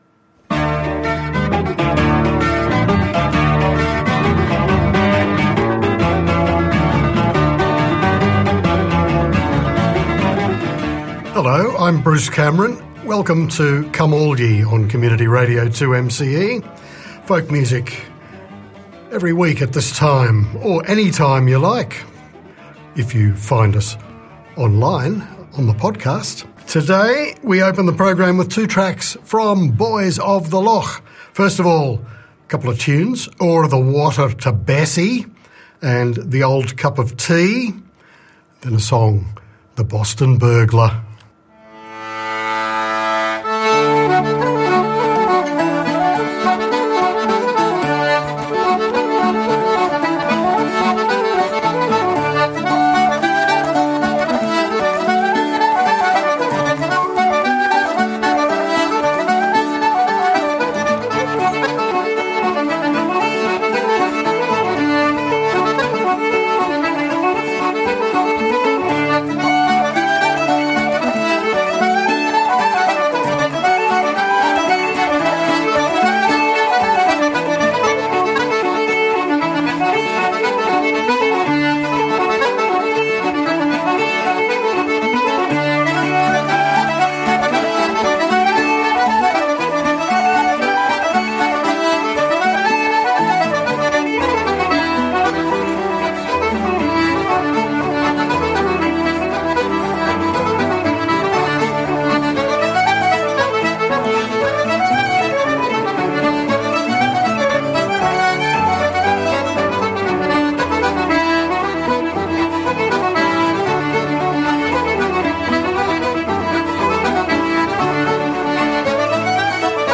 fiddler